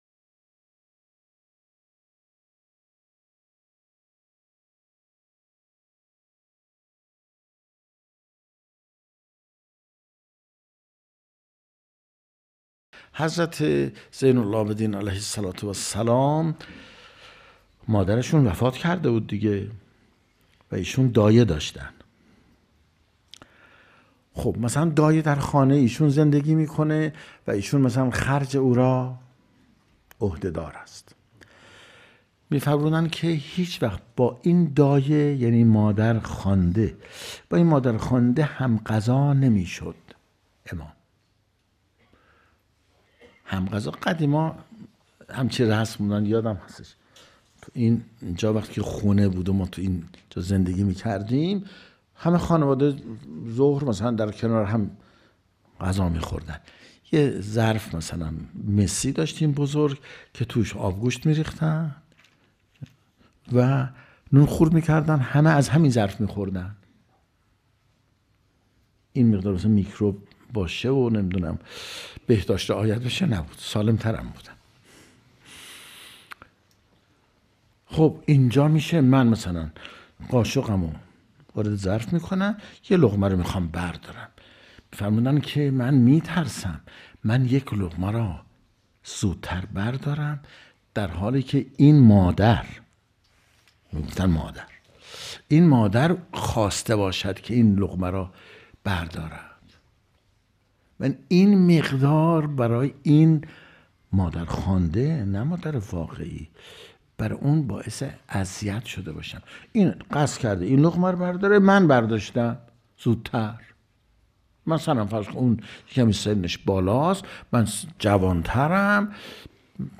سخنرانی | رفتار امام سجاد(ع) با مادرخوانده خود